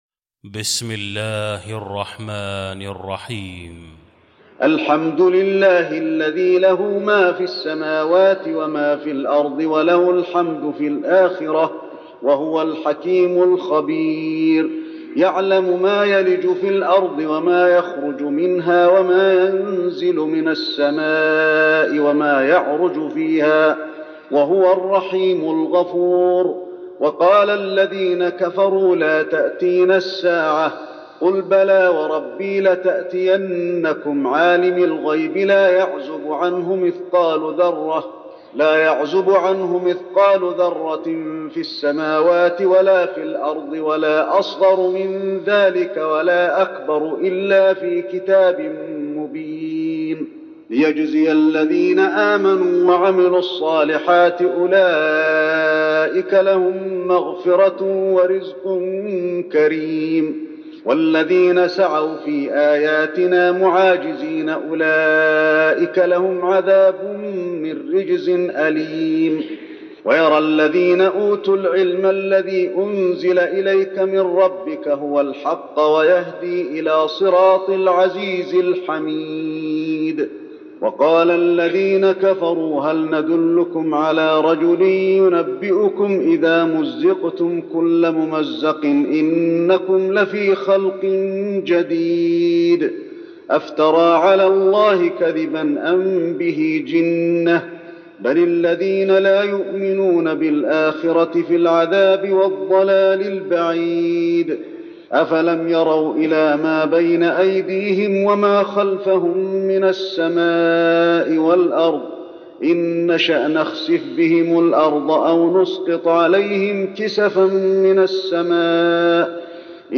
المكان: المسجد النبوي سبأ